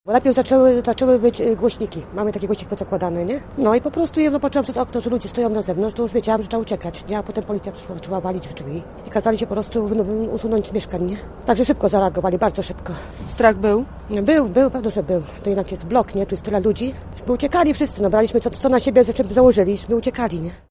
Przypomnijmy, pożar w Metalowcu wybuchł wczoraj około 20.30. – Gdy zawyły syreny wiedziałam, że trzeba uciekać – mówi nam dziś jedna z mieszkanek budynku: